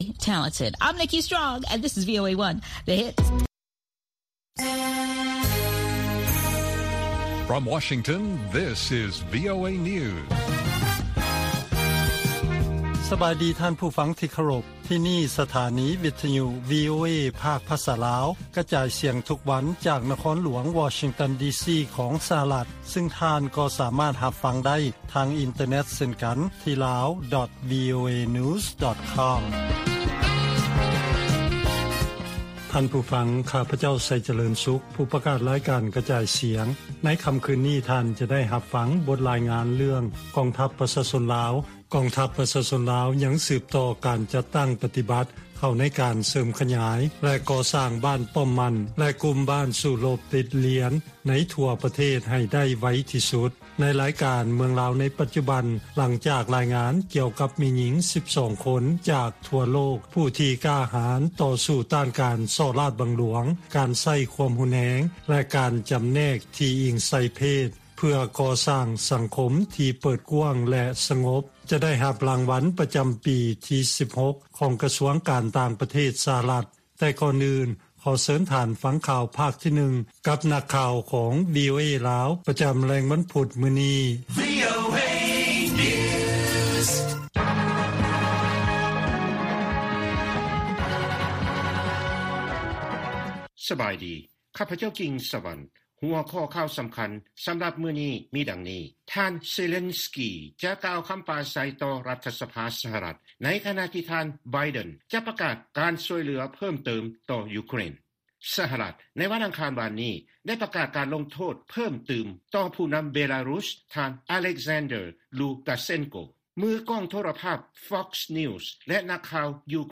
ລາຍການກະຈາຍສຽງຂອງວີໂອເອ ລາວ: ທ່ານ ເຊເລັນສກີ ຈະກ່າວຄຳປາໄສຕໍ່ ລັດຖະສະພາ ສຫລ, ໃນຂະນະທີ່ ທ່ານ ໄບເດັນ ຈະປະກາດການຊ່ວຍເຫຼືອເພີ່ມເຕີມ ຕໍ່ ຢູເຄຣນ